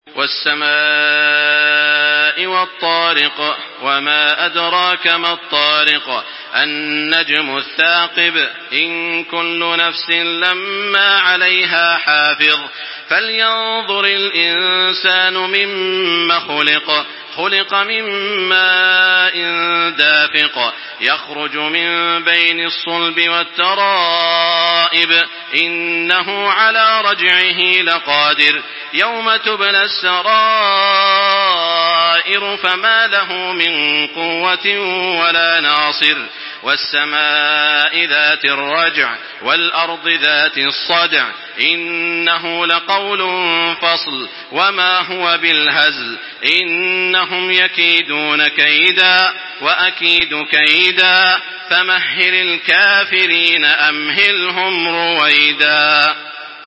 تحميل سورة الطارق بصوت تراويح الحرم المكي 1425
مرتل